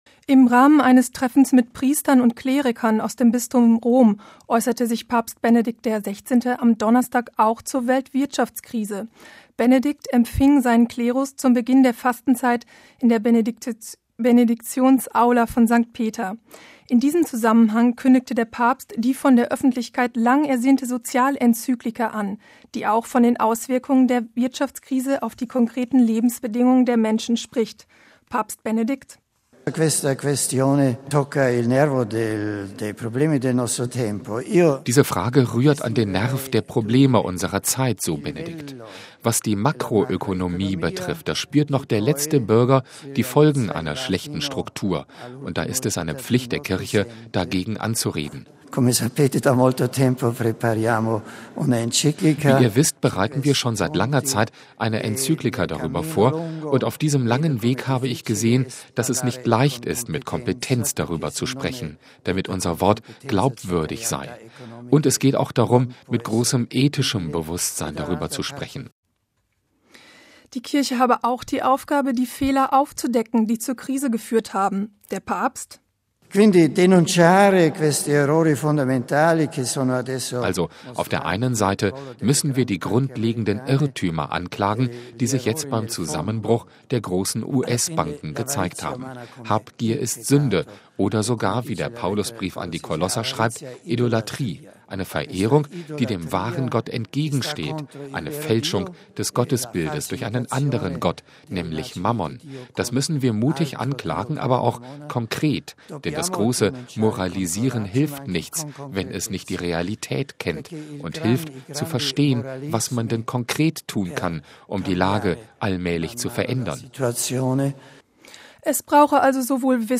Wie es mittlerweile Usus ist im Vatikan, empfing der römische Bischof seinen Klerus zum Beginn der Fastenzeit in der Benediktionsaula von St. Peter.
Das tut gut: Beifall für Benedetto.
Ein gemeinsames Angelus-Gebet und vereinzelte „Viva il Papa“-Rufe – das Treffen Benedikts mit seinem Klerus hat fast zwei Stunden gedauert.